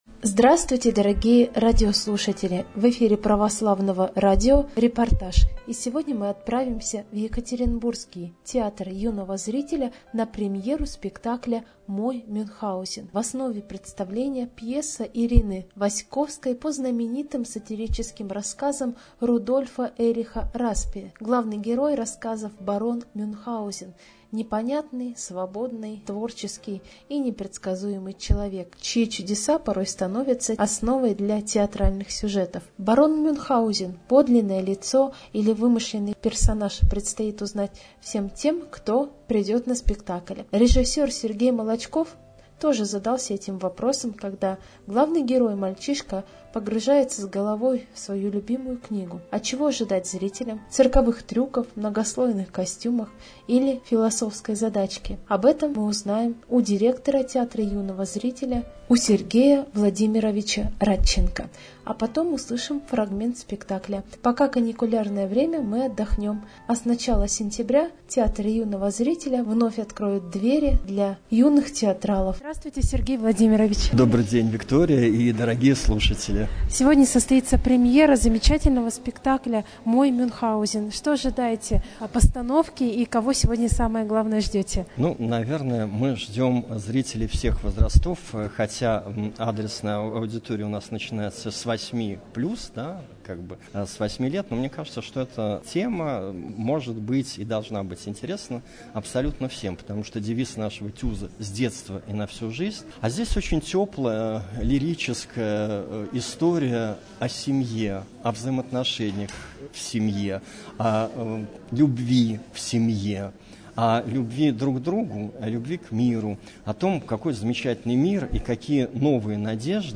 Репортаж дня | Православное радио «Воскресение»
Премьера спектакля "Мой Мюнхгаузен" в ТЮЗе
premera_spektaklya_moj_myunhgauzen_v_tyuze.mp3